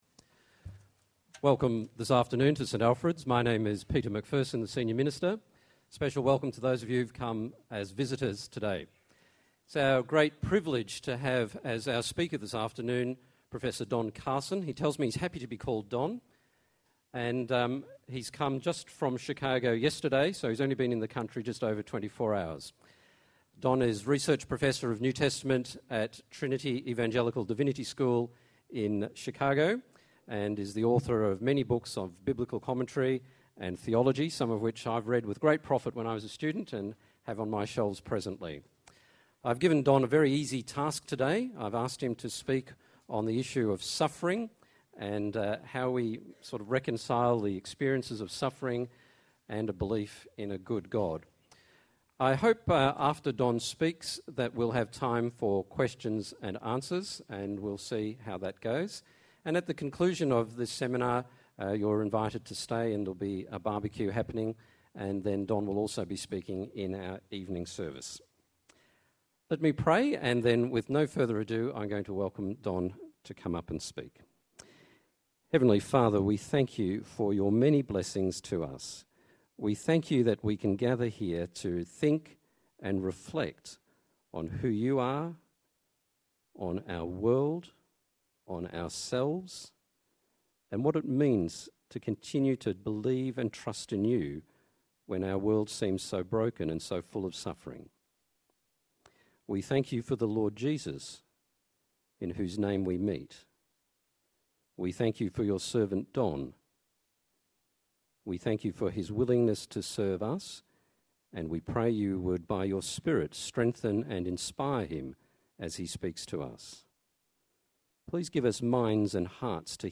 In this sermon, Don Carson speaks on the theme of 'Why suffering: A Theological Response?' as part of the series 'Questions and Answers'.